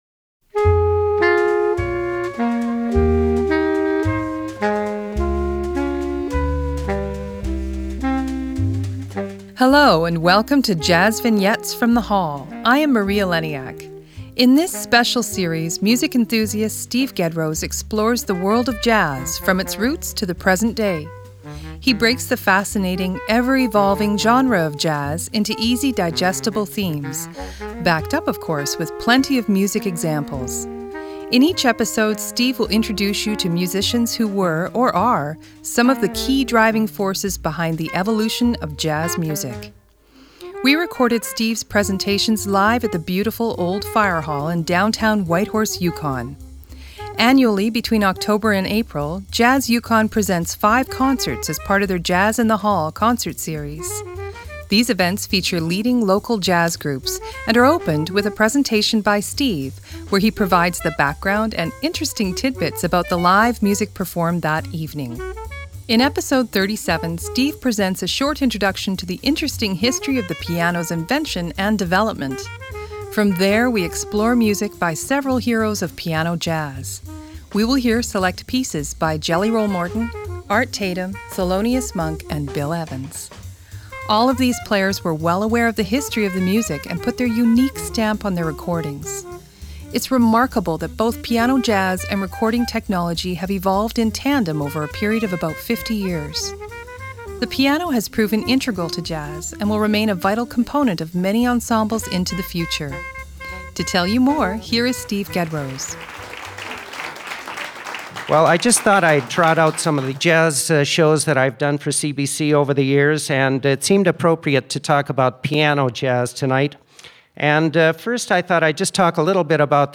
JVFTH_37_-_Piano_Jazz.mp3 72,247k 320kbps Stereo Comments
JVFTH_37_-_Piano_Jazz.mp3